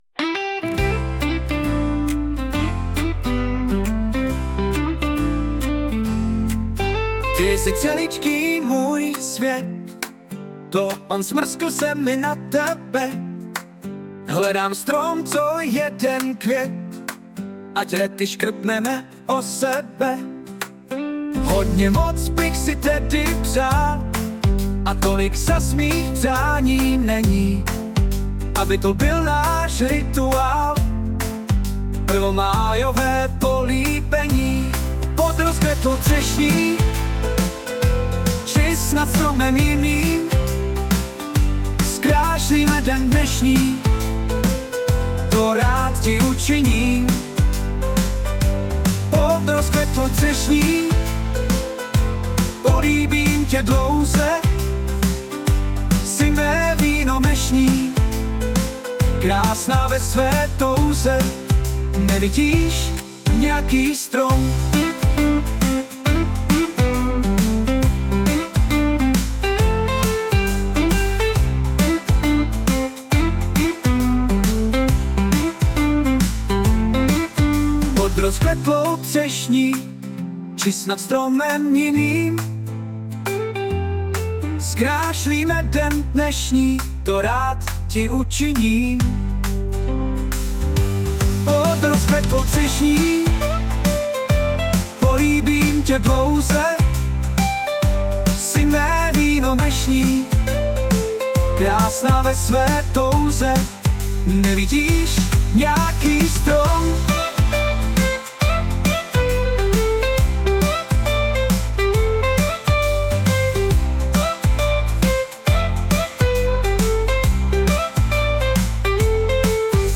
* hudba, zpěv: AI